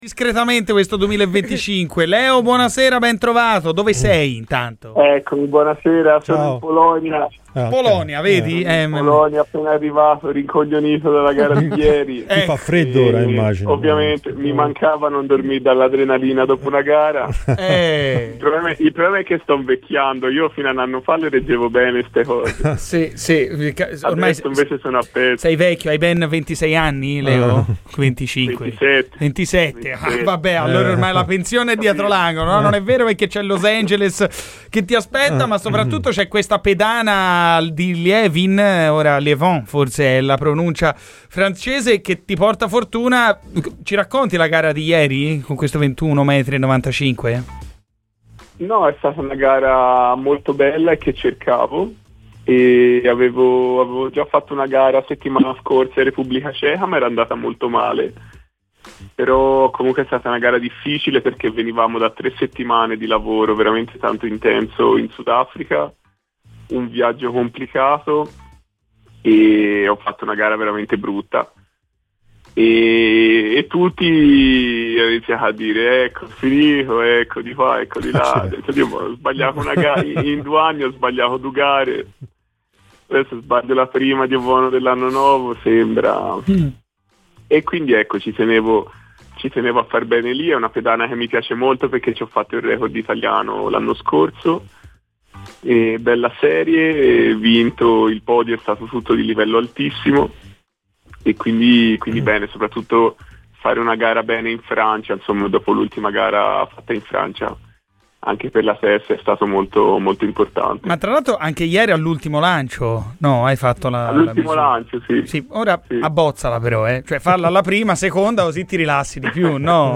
Il pesista Leonardo Fabbri, autore ieri della vittoria al meeting di Liévin con la migliore prestazione dell'anno con il lancio di 21.95, è intervenuto a "Garrisca al Vento" su Radio FirenzeViola per raccontare le sue emozioni e parlare di Fiorentina: "Ora sono in Polonia, a pezzi dopo la gara di ieri.